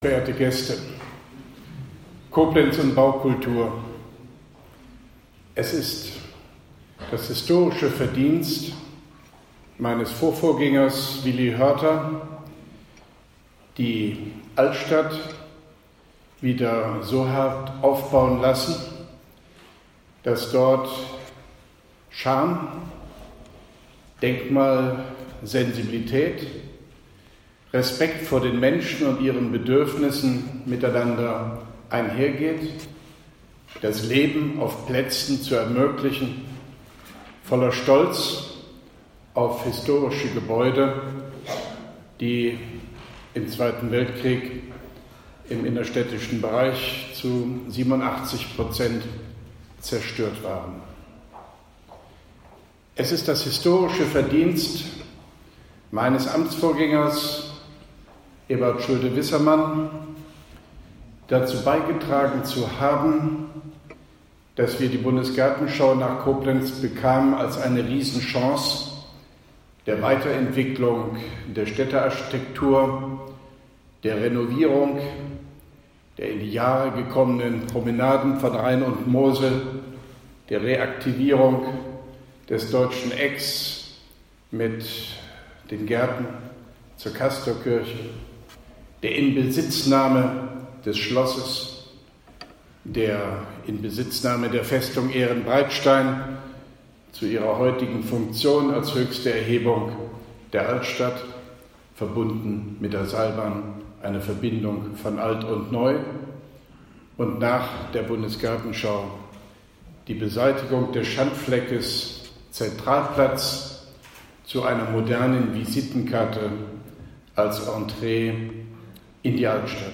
Auszug: Grußwort von OB Hofmann-Göttig beim Koblenzer Forum für Architektur und Städtebau “Schaufenster Baukultur: BUGA Heilbronn 2019” Koblenz 31.03.2017